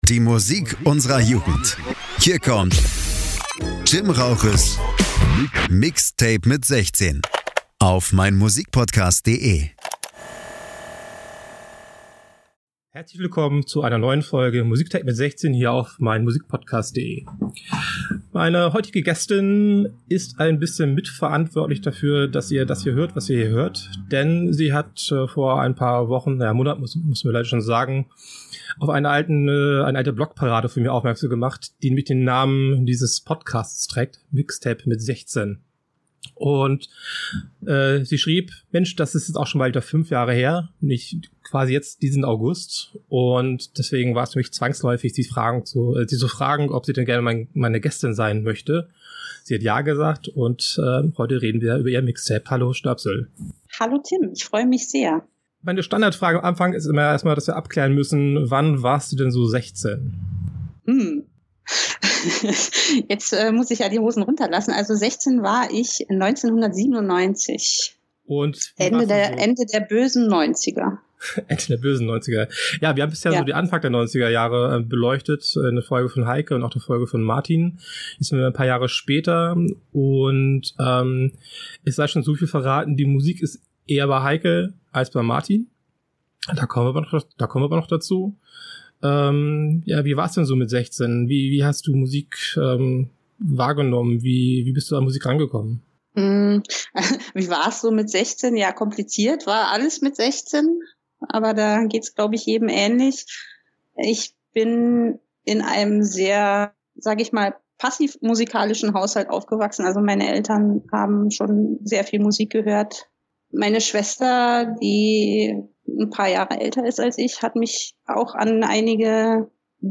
Desweiteren habe ich mich bemüht die Musikausschnitte diesmal etwas lauter zu machen.